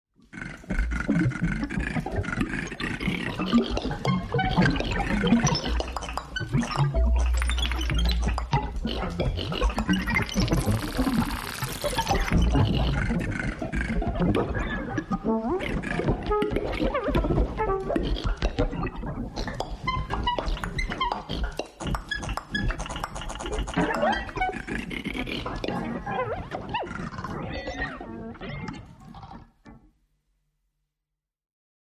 at morphine raum, berlin